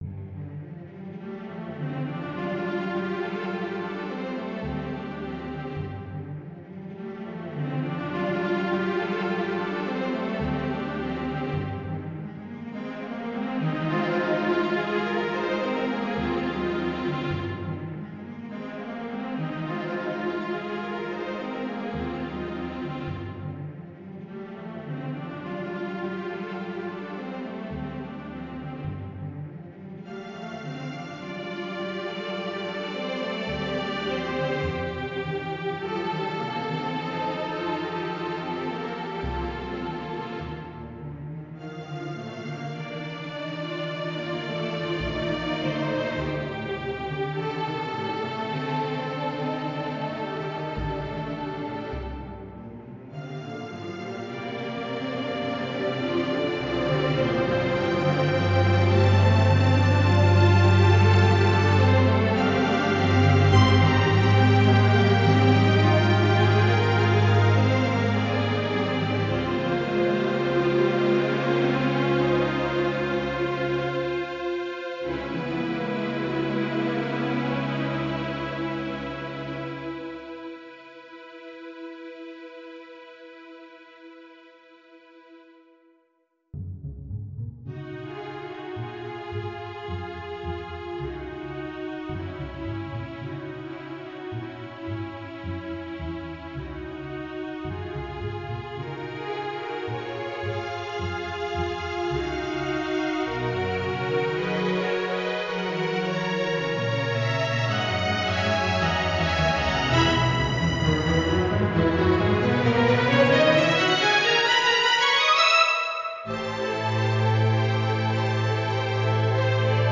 String Orchestra - I have often contemplated the sea, gazing upon its limitless expanse as it stretches away to the horizon and beyond.
In Ocean of Possibilities, I strove to capture the ebb and flow of the unceasing yet never the same rhythmic pulse of the sea when it breaks upon the shore. The lyricism that floats above this constant movement of the water represents the potential of the ocean for sustenance, for comfort, for inexorable force and power, for infinite calm and peacefulness.